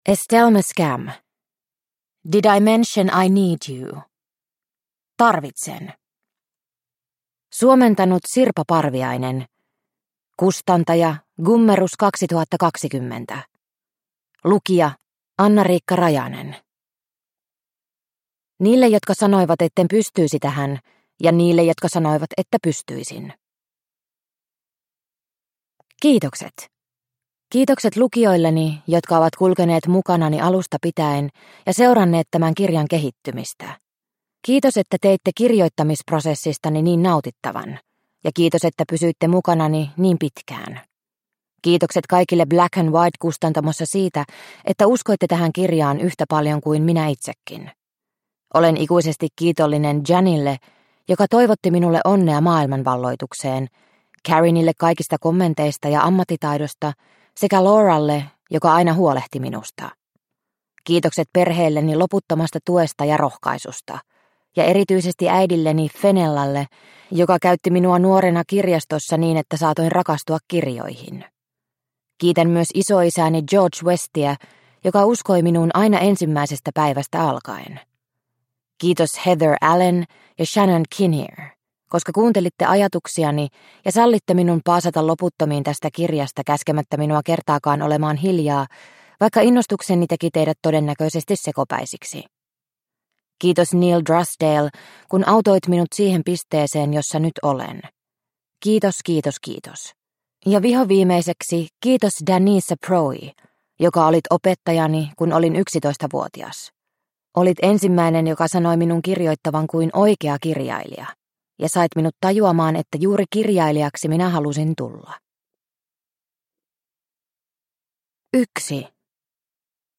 DIMINY - Tarvitsen – Ljudbok – Laddas ner